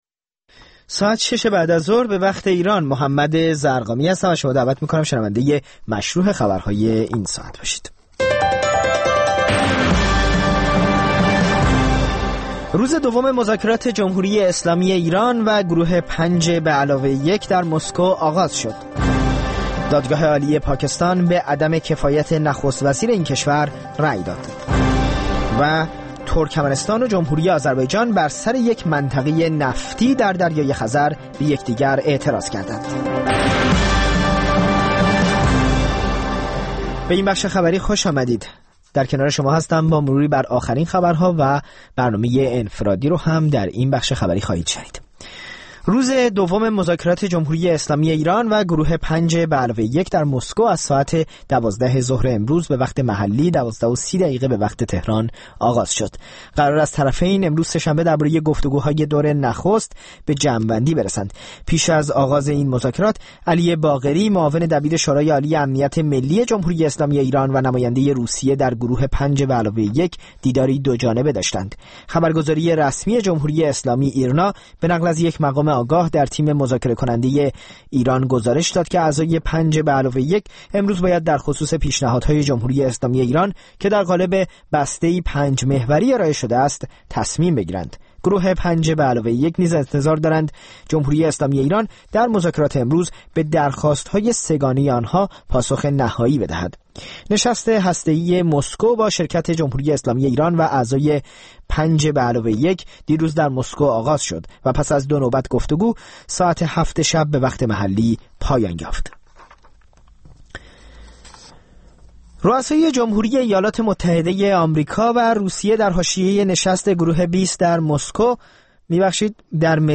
مستند رادیویی «انفرادی» هر هفته سه‌شنبه‌ها در ساعت ۱۸ به وقت ایران پخش می‌شود و روزهای پنجپنج‌شنبه ساعت ۱۴، روزهای جمعه ساعت ۹ صبح و یکشنبه‌ها ساعت ۲۳ به وقت ایران، تکرار می‌شود.